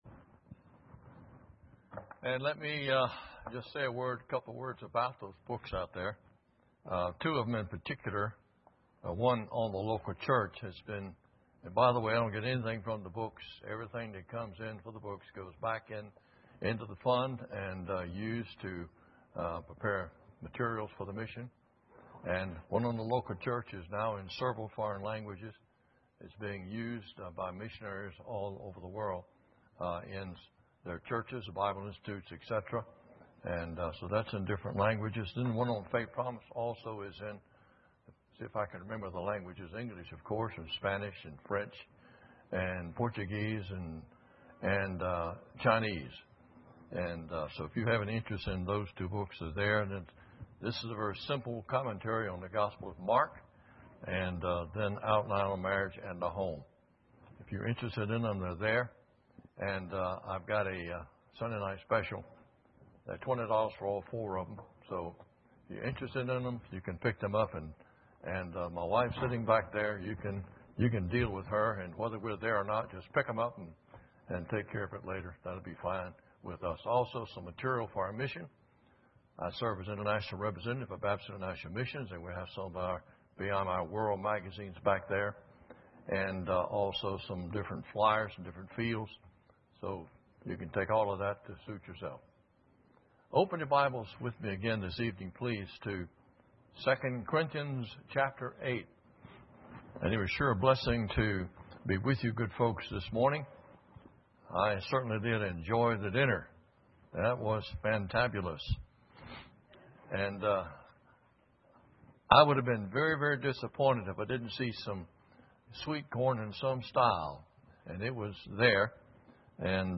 2009 Missions Conference Service Type: Sunday Evening Preacher